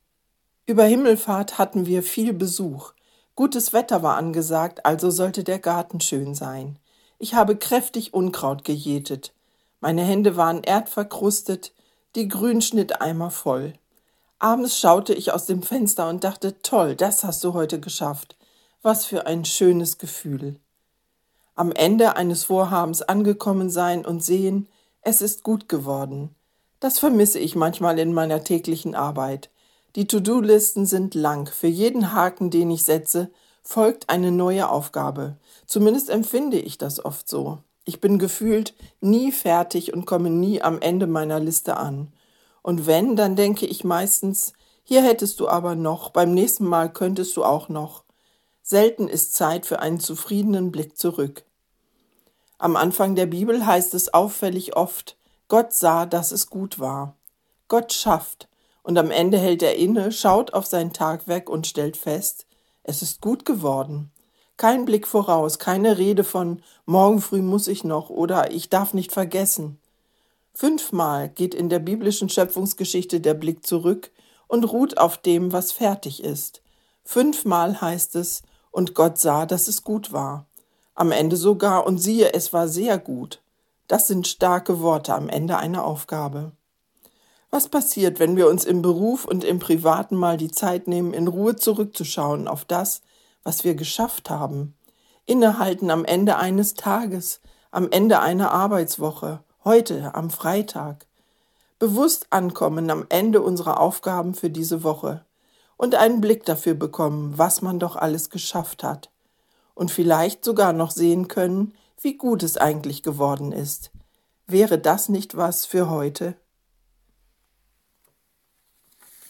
Radioandacht vom 24. Mai